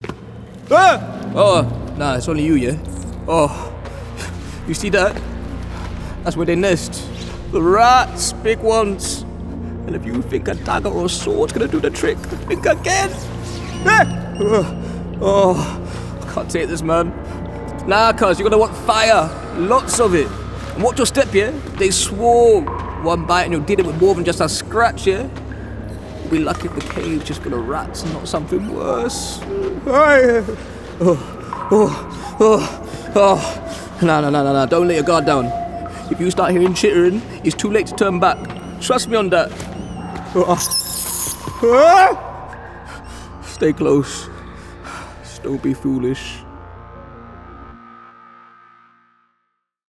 MLE Accent Showreel
Male
Multicultural London English (MLE)
3-mle-accent-reel.wav